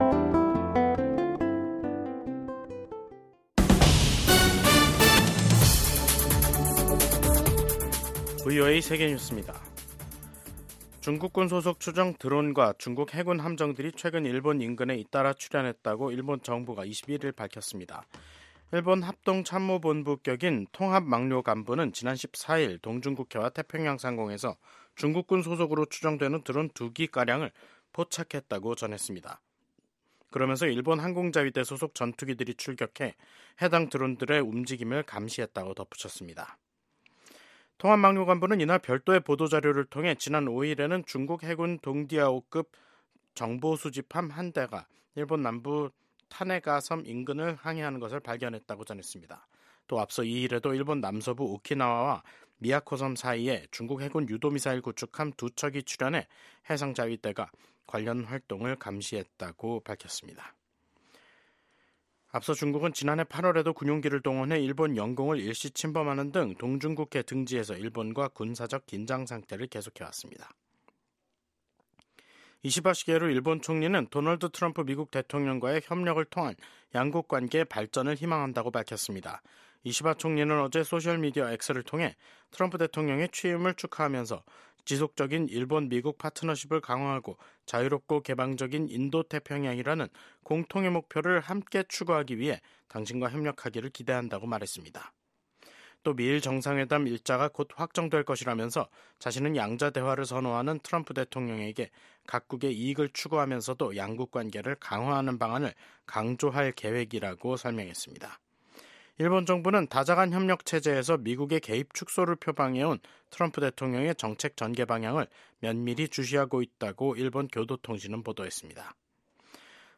VOA 한국어 간판 뉴스 프로그램 '뉴스 투데이', 2025년 1월 21일 3부 방송입니다. 도널드 트럼프 미국 대통령이 4년 만에 백악관에 복귀했습니다. 취임 첫날 트럼프 대통령은 북한을 ‘핵보유국’이라고 지칭해 눈길을 끌었습니다.